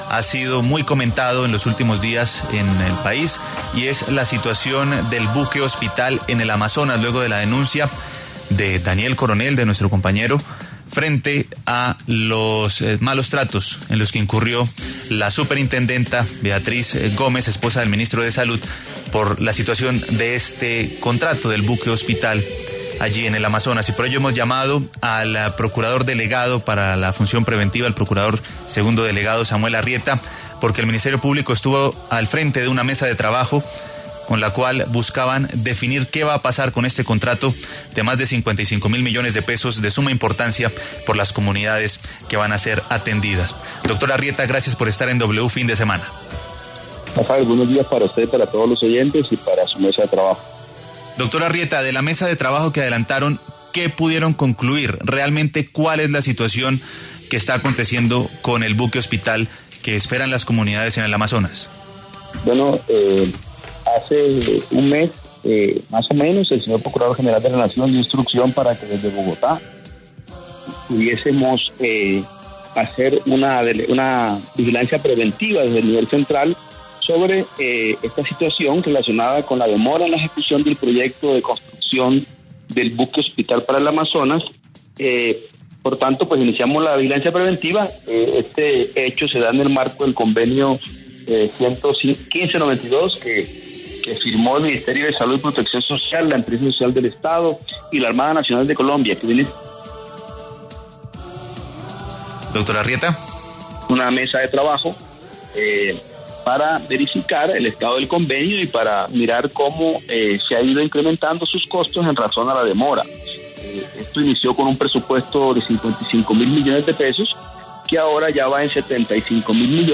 W Fin De Semana conversó con el procurador segundo delegado para la Función Preventiva, quien dio detalles del caso.
Samuel Arrieta, procurador segundo delegado para la Función Preventiva, pasó por los micrófonos de W Fin De Semana para hablar de los hallazgos del Ministerio Público frente a la polémica del llamado Buque Hospital en el Amazonas, en donde el ministerio público sentó al ministerio de Salud, el Hospital San Rafael de Leticia y la Armada Nacional para resolver el futuro de ese importante proyecto.